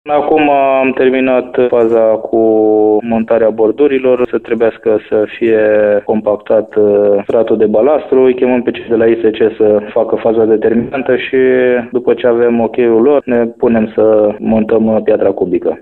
Peste două luni, centrul staţiunii ar trebui să fie gata, spune primarul din Băile Herculane, Cristian Miclău.